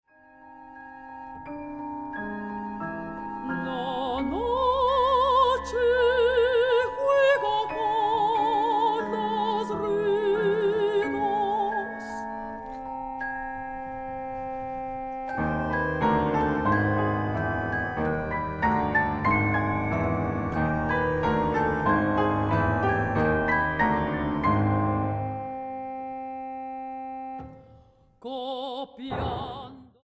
para contralto, piano, armonio y celesta.
mezzosoprano